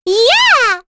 Rehartna-Vox_Happy8.wav